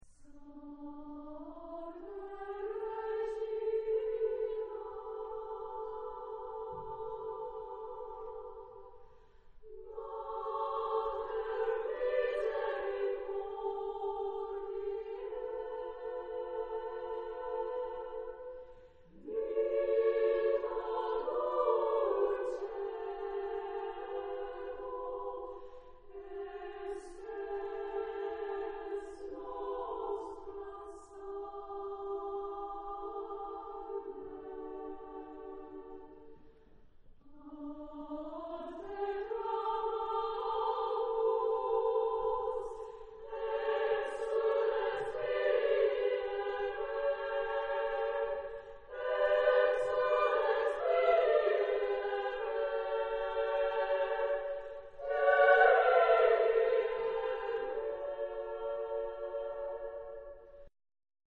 Genre-Style-Form: Sacred ; Contemporary
Type of Choir:  (3 equal voices )